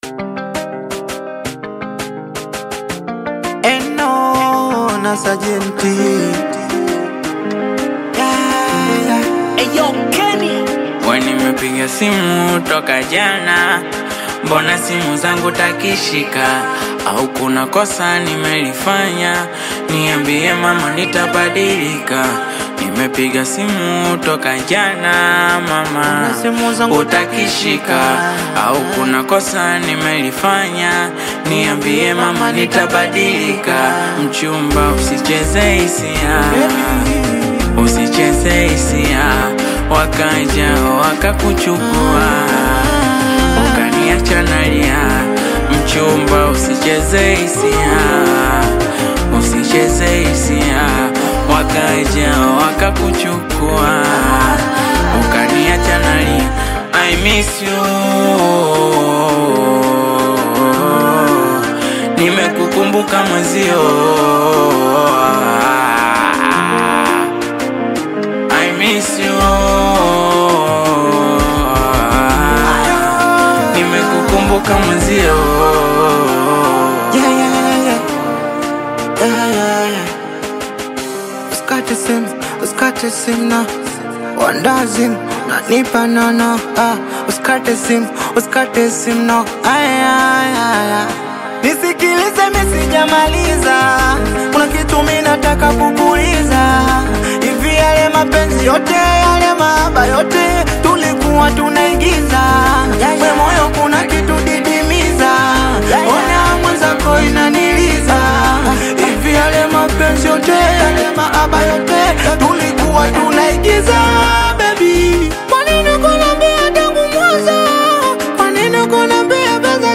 soulful Tanzanian Singeli/Bongo Flava collaboration
smooth vocal delivery
expressive verses